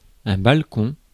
Ääntäminen
IPA: /bal.kɔ̃/